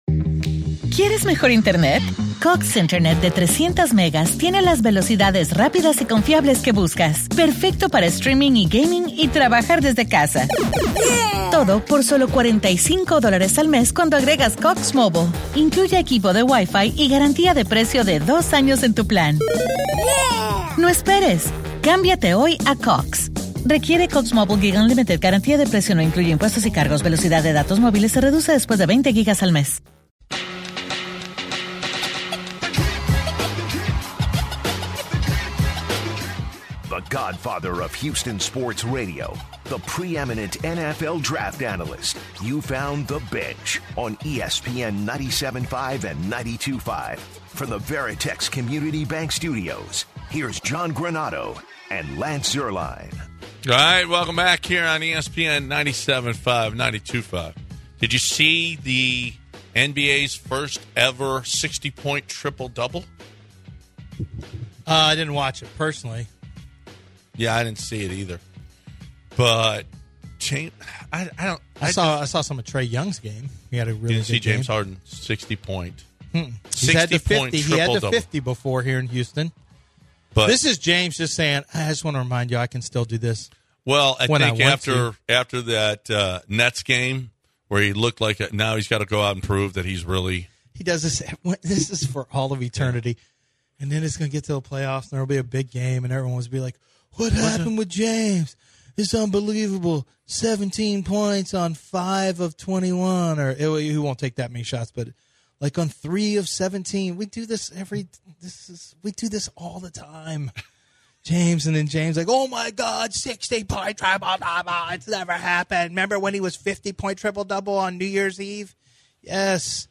University of Houston Head Basketball coach Kelvin Sampson joins the show to discuss their teams tournament success.